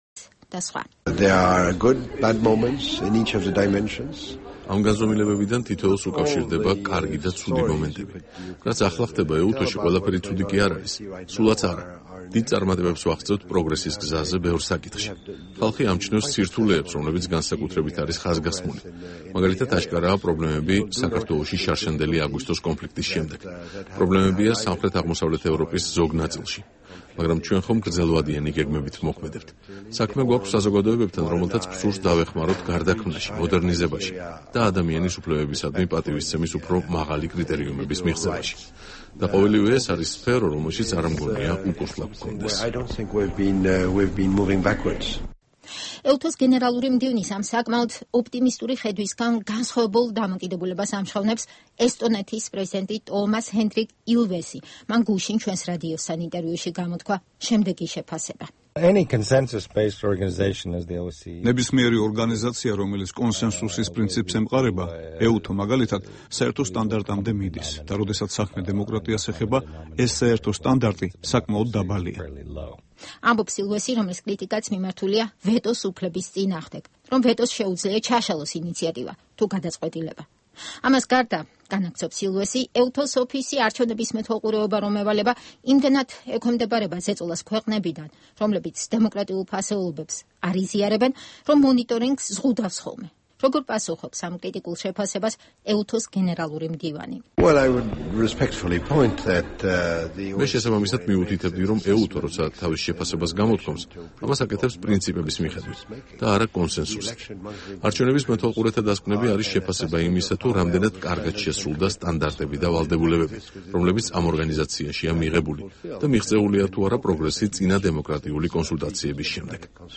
“კარგი და ცუდი მომენტები” - ინტერვიუ ეუთოს გენერალურ მდივანთან